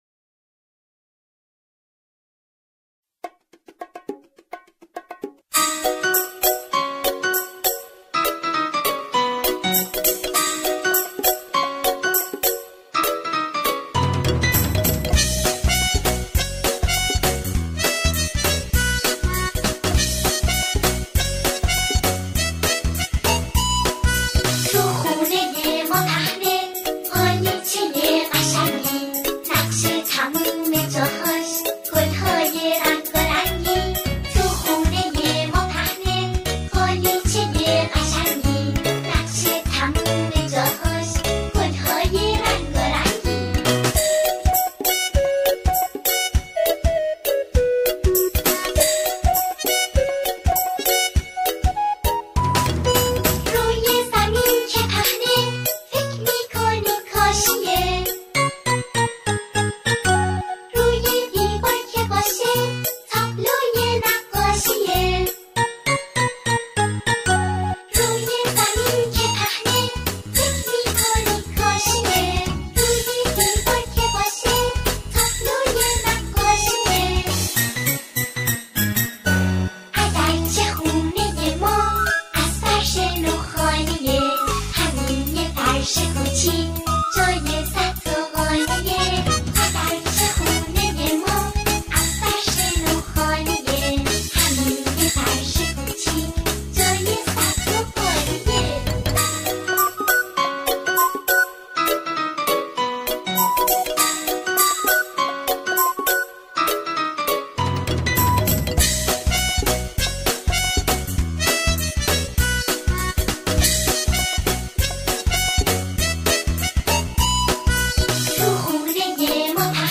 سرود های کودک
خوانندگان، این قطعه را با شعری کودکانه اجرا می‌کنند.